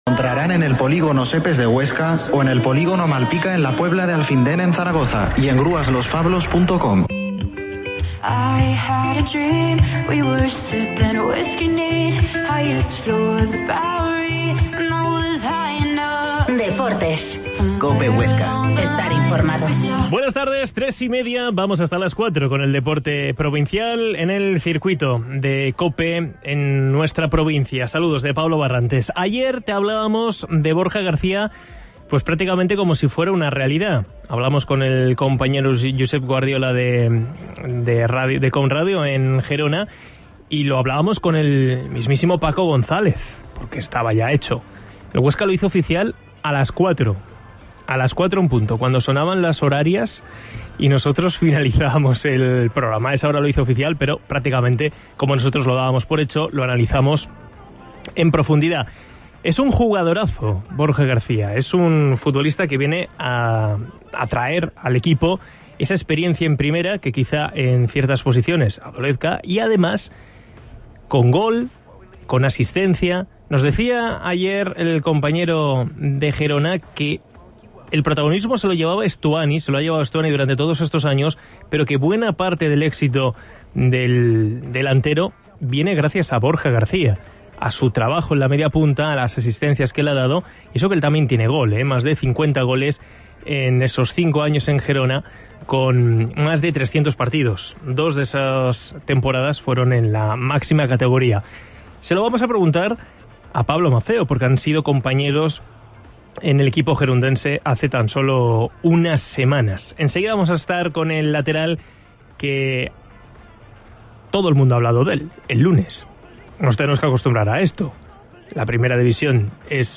Entrevista a Pablo Maffeo, jugador de la SD Huesca